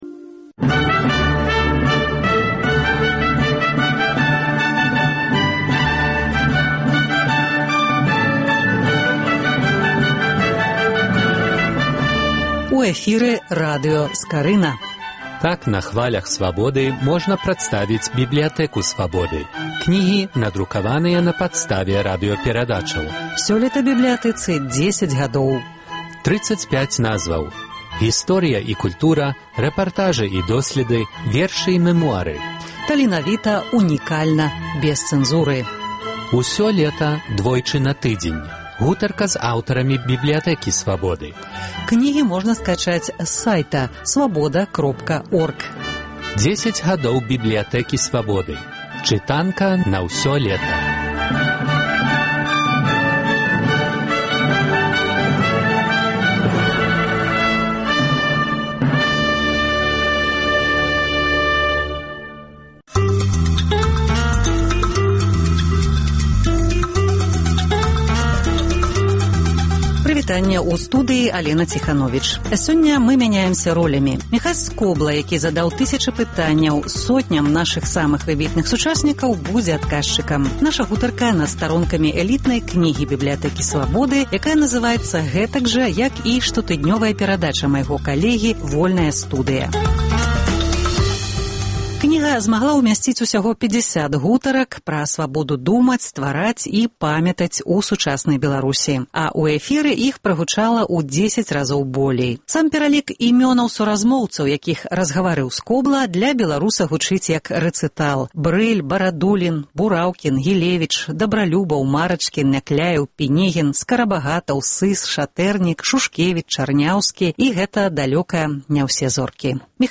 Працяг радыёсэрыі “10 гадоў “Бібліятэкі Свабоды”. Гутарка